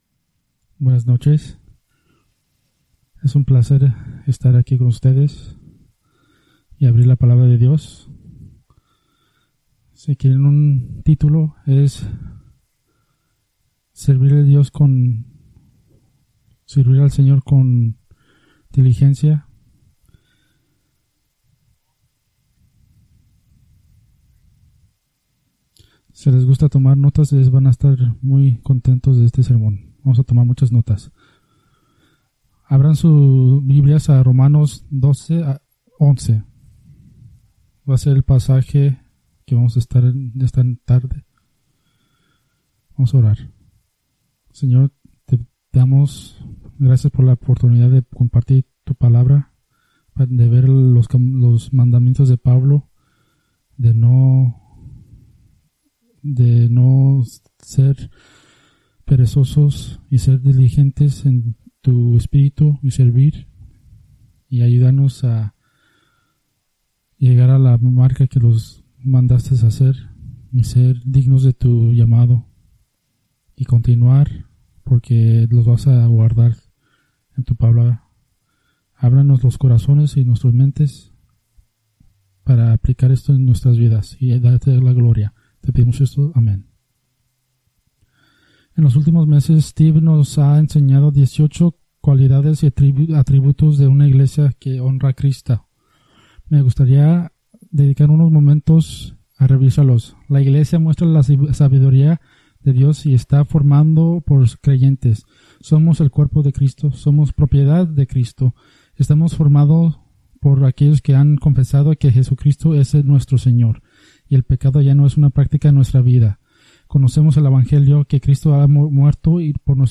Preached June 2, 2024 from Escrituras seleccionadas